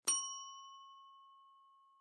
nastolnyi_zvonok.ogg